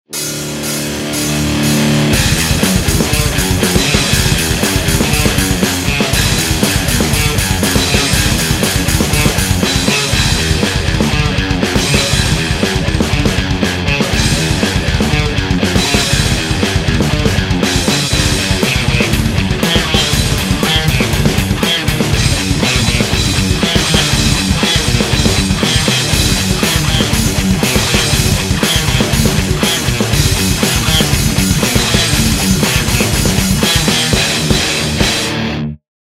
metal
track in metal style.